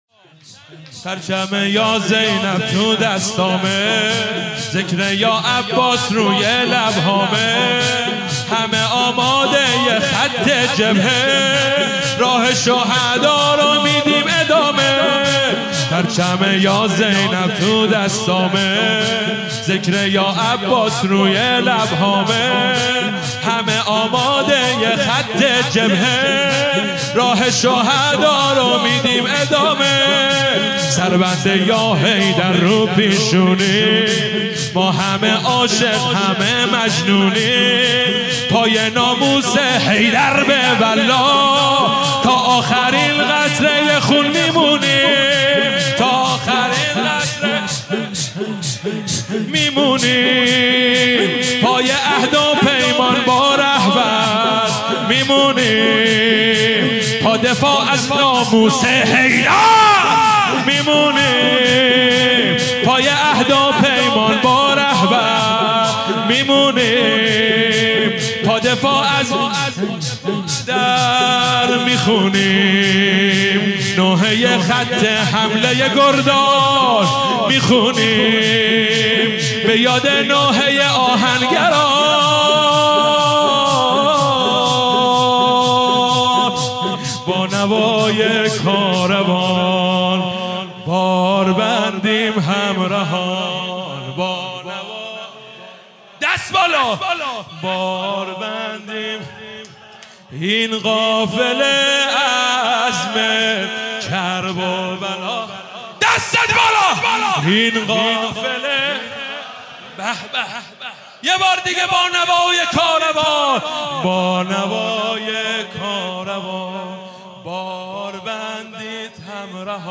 مداحی انقلابی